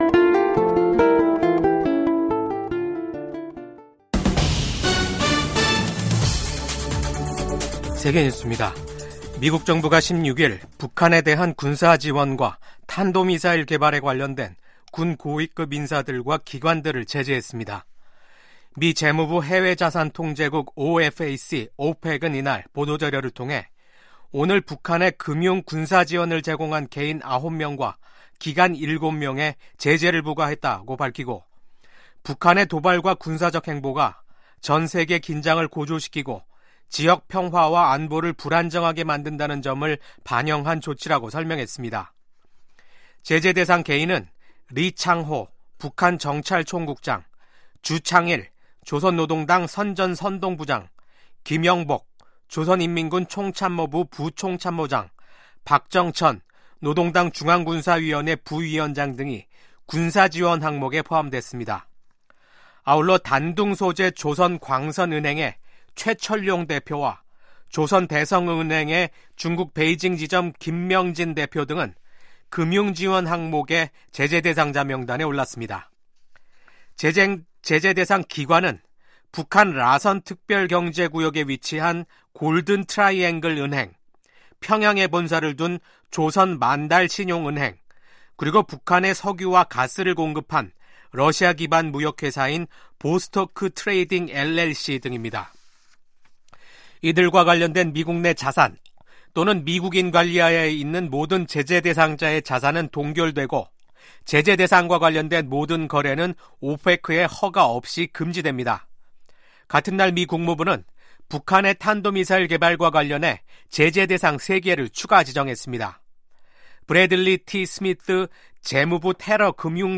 VOA 한국어 아침 뉴스 프로그램 '워싱턴 뉴스 광장'입니다. 윤석열 한국 대통령이 탄핵소추안 가결로 직무가 정지되면서 윤석열 정부가 추진해 온 강경기조의 대북정책 동력도 약화될 것이라는 전망이 나옵니다. 미국 정부는 윤석열 대통령 탄핵소추안이 한국 국회에서 가결된 것과 관련해 한국의 민주주의와 법치주의에 대한 지지 입장을 재확인했습니다.